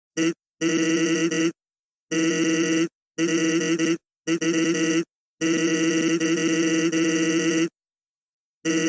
PLAY Just Sans talking
sans-talking.mp3